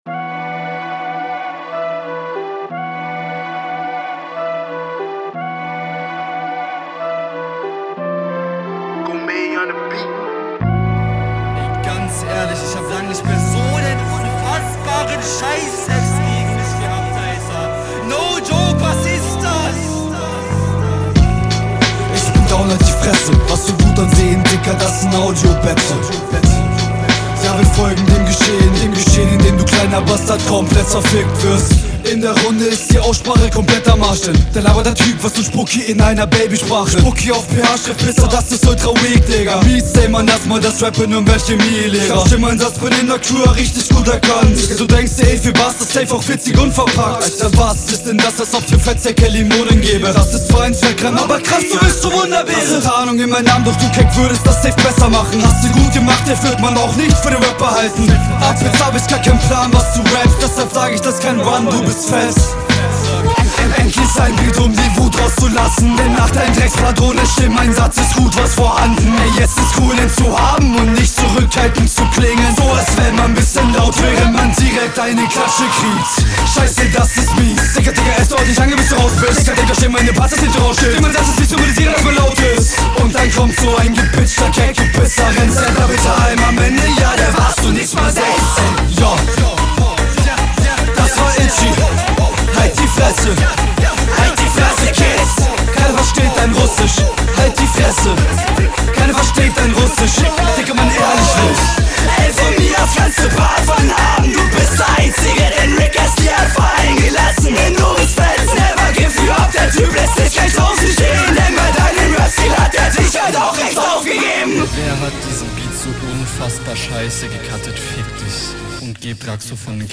Stabile Runde, Soundqilität ist halt ziemlich trash und Cuts hört man deutlich raus.